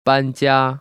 [bān//jiā]